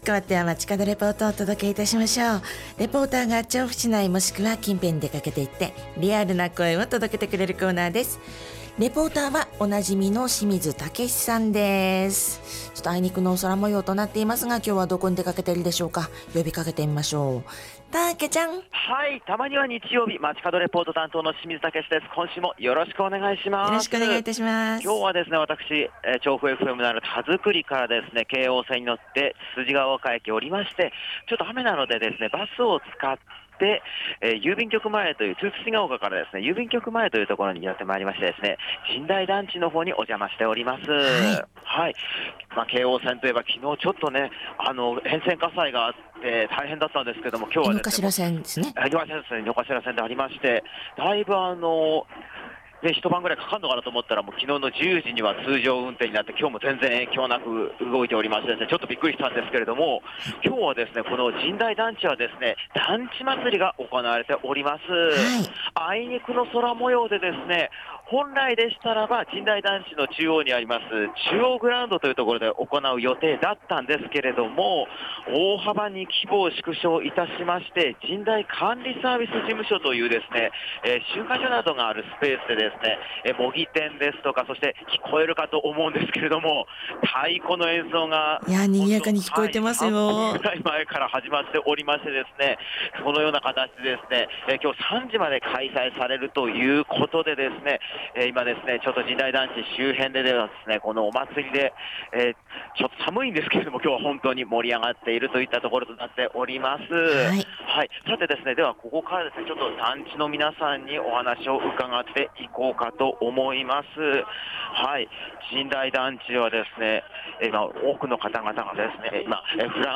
コートが必須の様な寒い空の下からお届けした街角レポートは、 神代団地中央広場…奥にある神代団地事務所・集会所スペースで開催された 「団地祭」の会場からお届けしました！